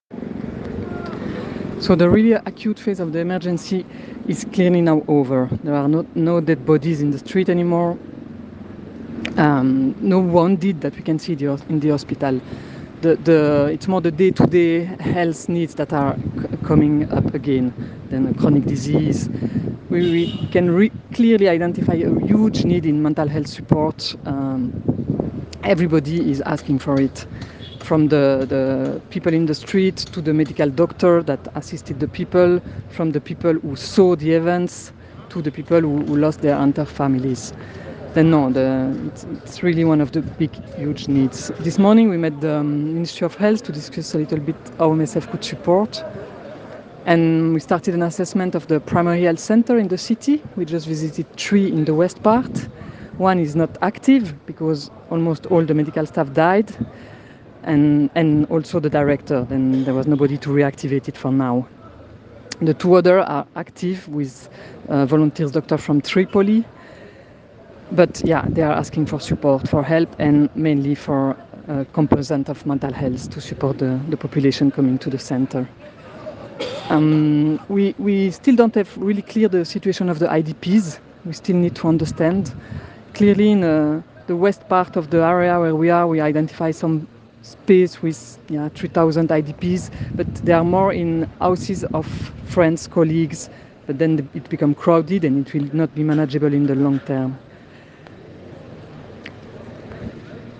Libyen: Bericht des Teams von Ärzte ohne Grenzen aus Darna – Audio